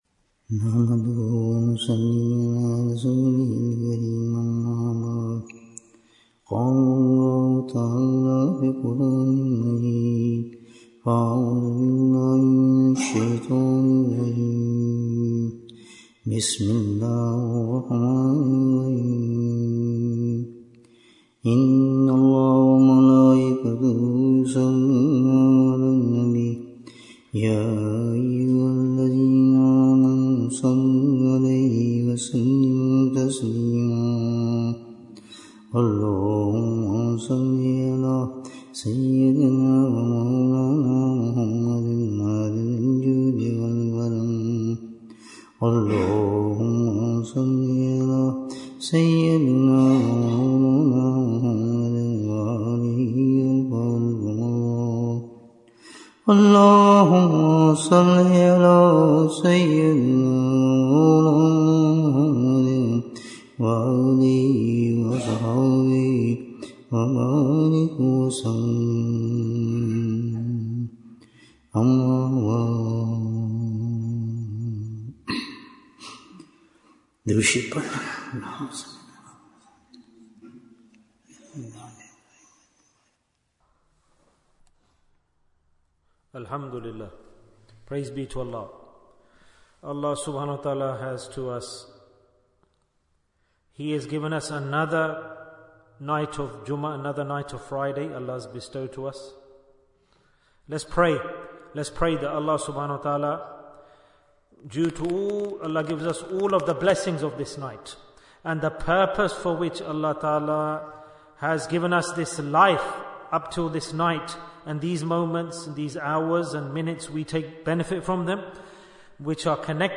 On Which Amal Does All Deen Depend? Bayan, 119 minutes28th November, 2024